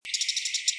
62-2紅尾伯勞1衛武營2012apr1.WAV
紅尾伯勞(普通亞種) Lanius cristatus lucionensis
高雄市 鳳山區 衛武營
錄音環境 公園樹上
行為描述 鳴叫